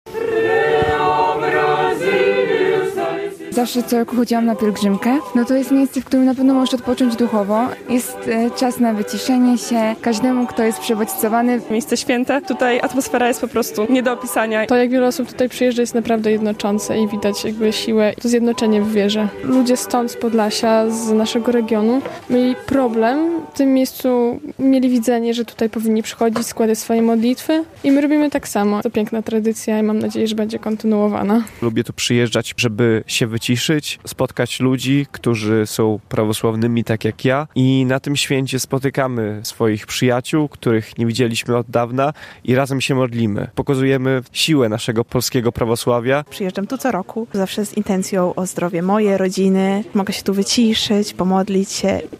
Na Świętej Górze Grabarce zakończyły się dwudniowe uroczystości z okazji święta Przemienienia Pańskiego.